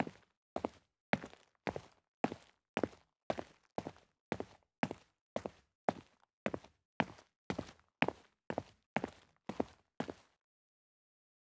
Player Foot sound.wav